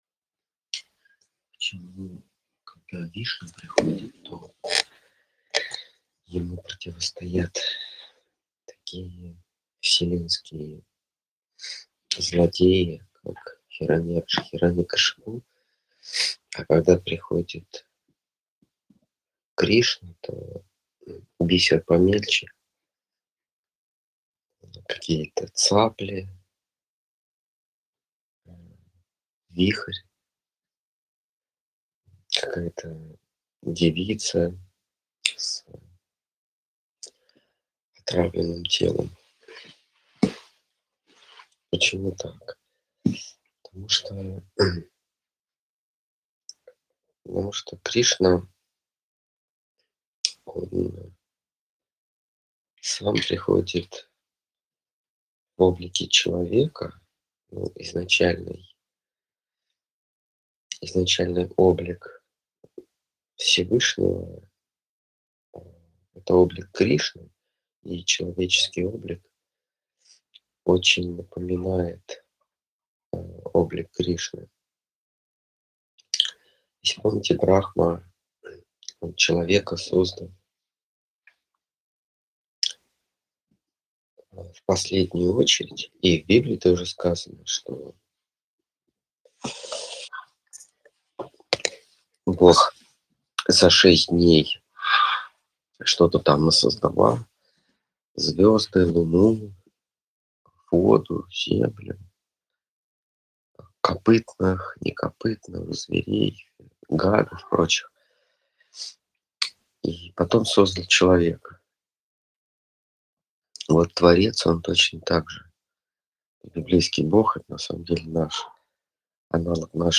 Ответы на вопросы из трансляции в телеграм канале «Колесница Джаганнатха».